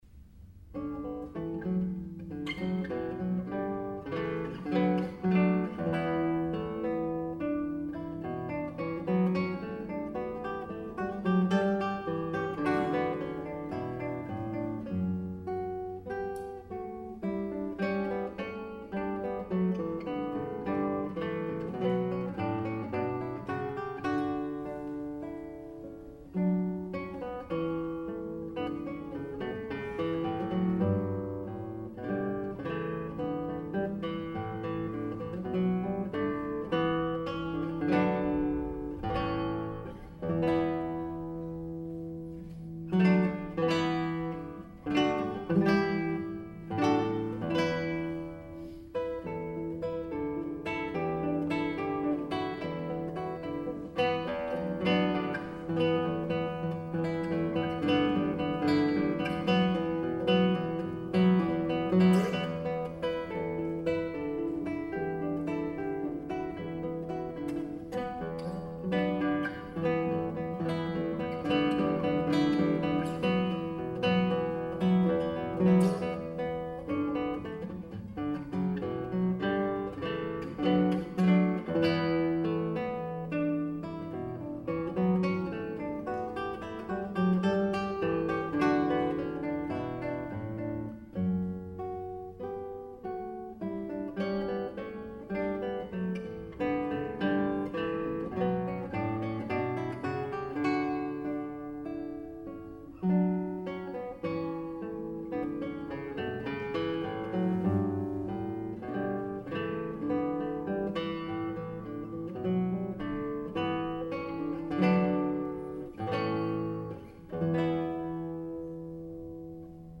Triptych for solo guitar
premiere performance